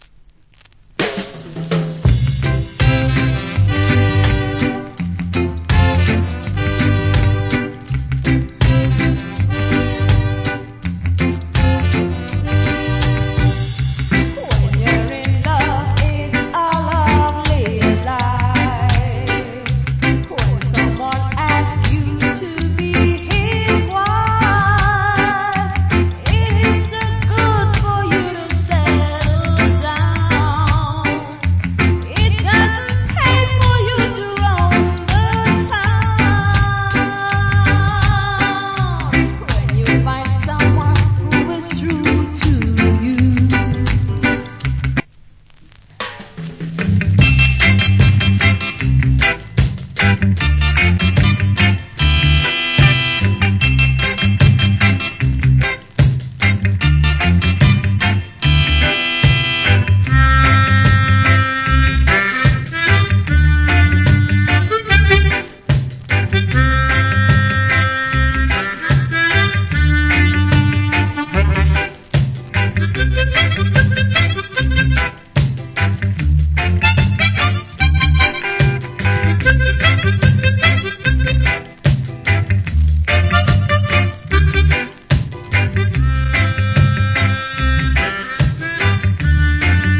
Foundation Rock Steady Vocal.